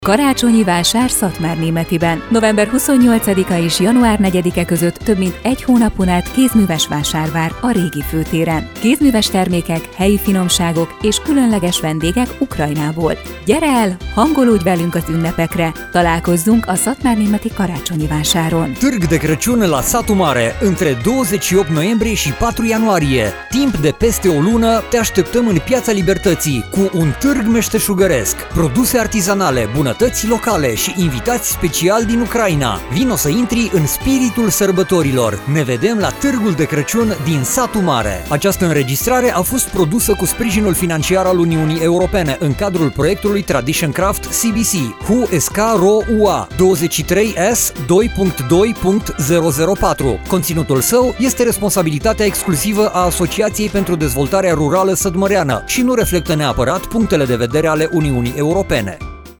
Radio spot - Târg de Crăciun - Karácsonyi Vásár